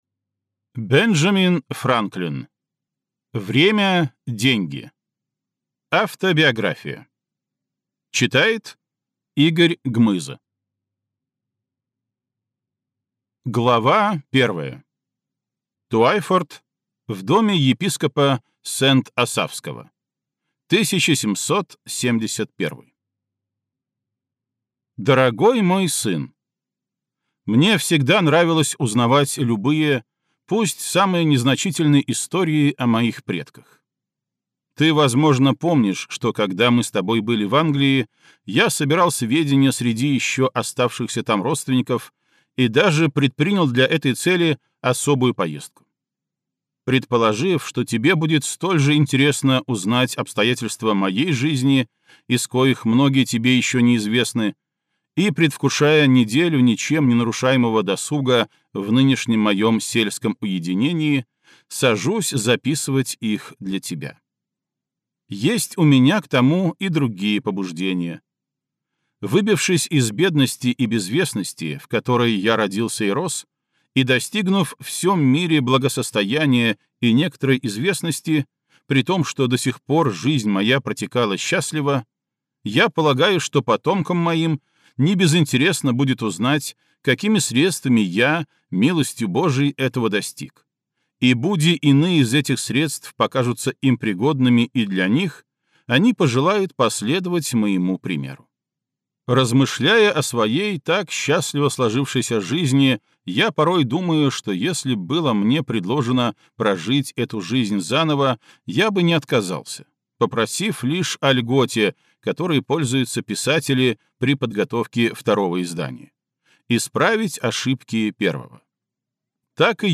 Аудиокнига Время – деньги!